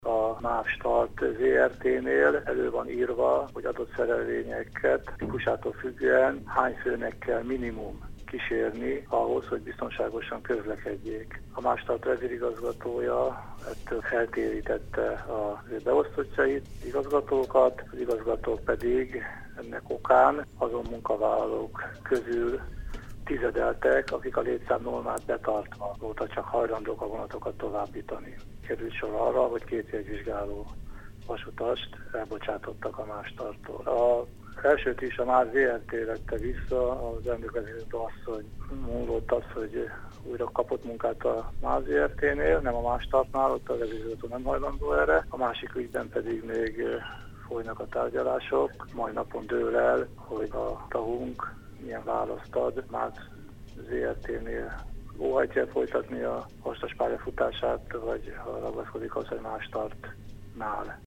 Az interjút